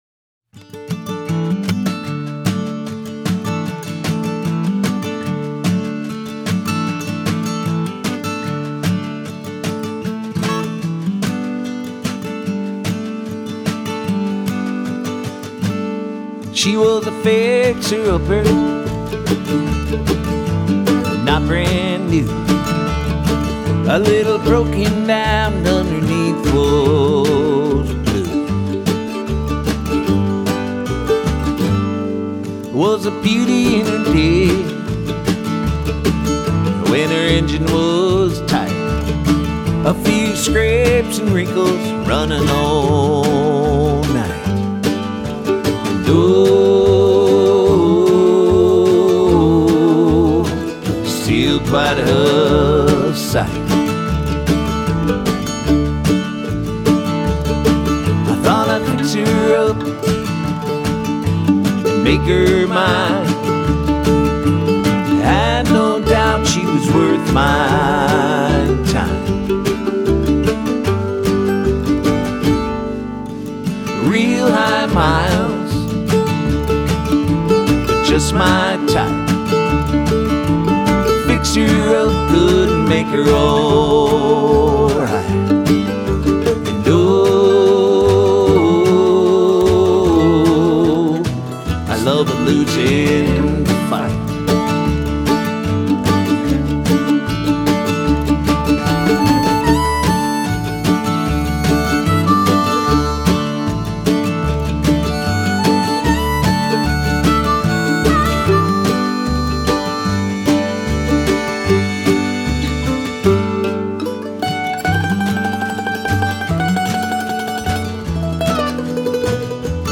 Minnesota Folk Singer and Songwriter